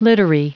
Prononciation du mot littery en anglais (fichier audio)
Prononciation du mot : littery